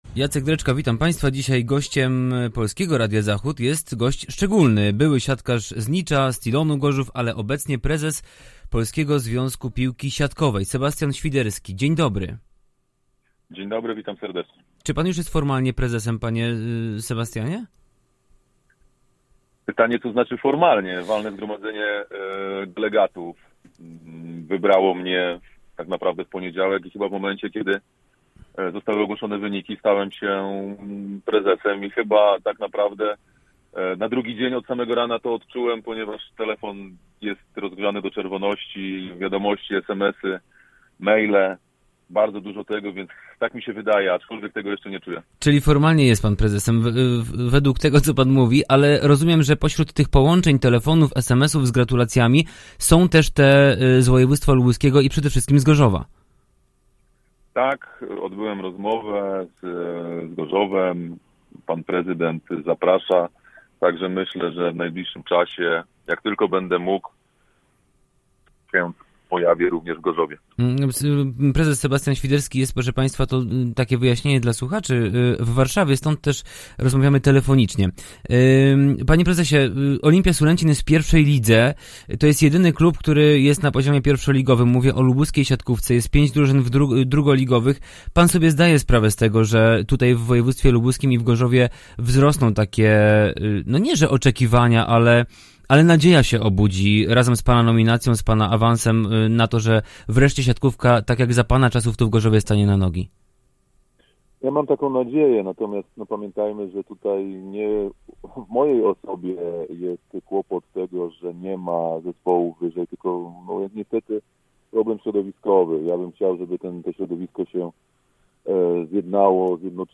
Sebastian Świderski, prezes Polskiego Związku Piłki Siatkowej